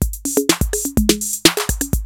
TR-808 LOOP1 3.wav